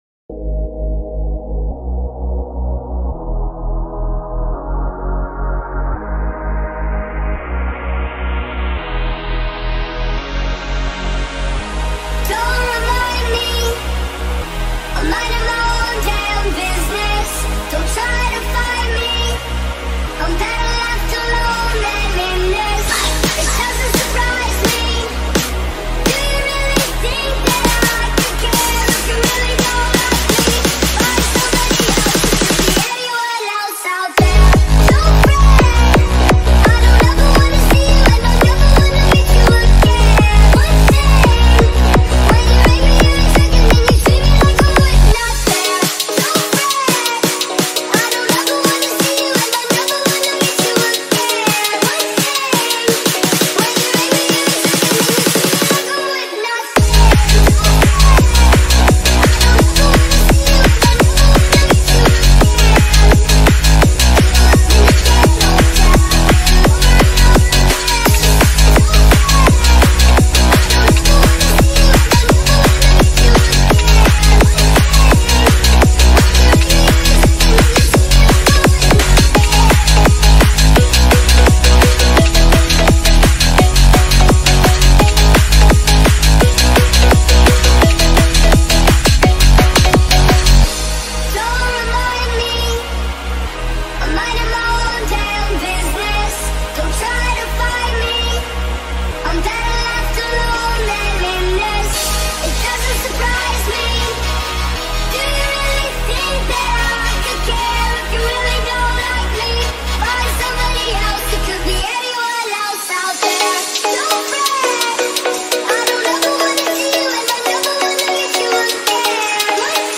Hardstyle